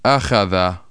ajadha ÃÎóÐó